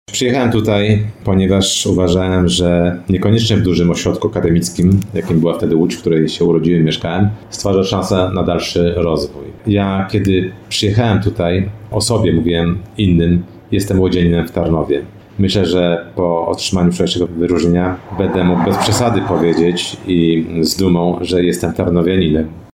Kiedy odbierał statuetkę, mówił Radiu RDN Małopolska o swoich początkach w Tarnowie.